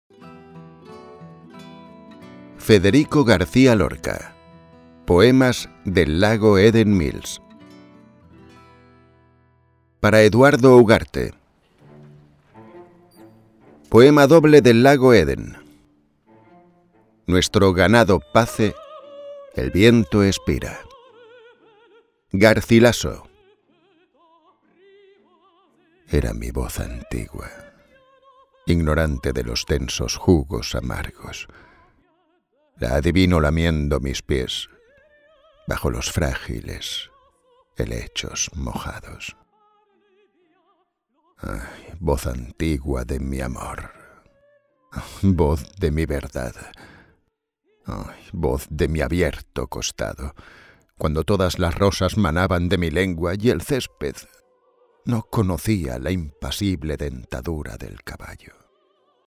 Audiolibro Poemas del lago Eden Mills de Federico García Lorca